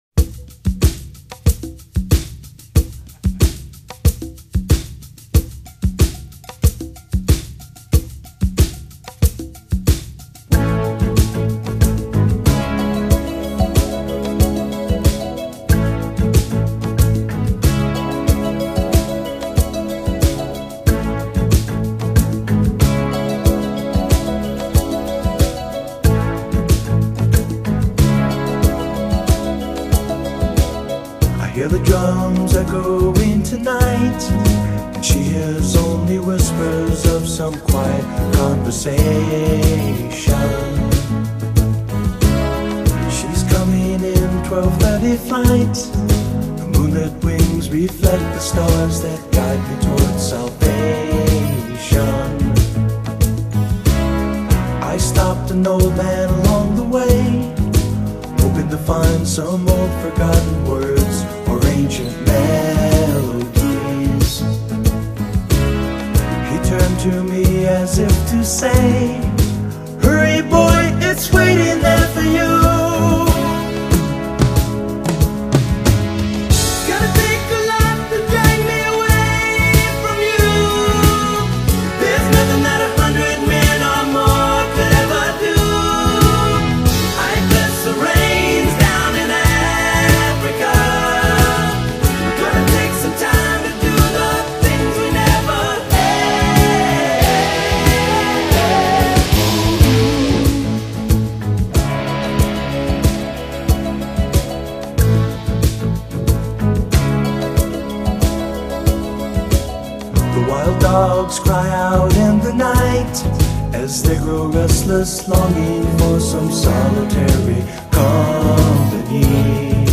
BPM92
Audio QualityCut From Video